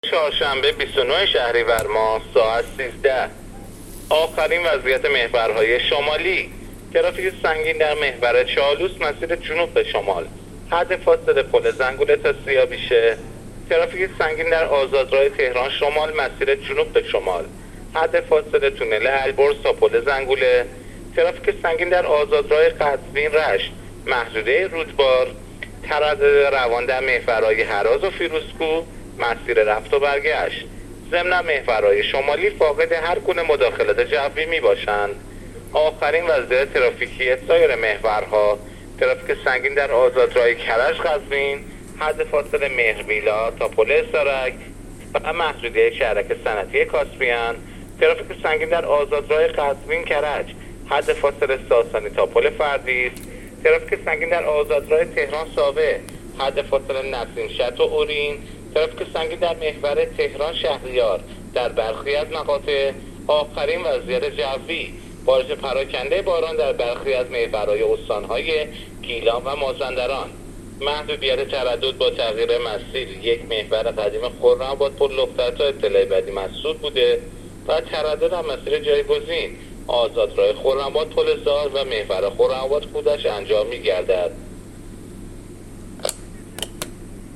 گزارش رادیو اینترنتی از آخرین وضعیت ترافیکی جاده‌ها تا ساعت ۱۳ بیست و نهم شهریور؛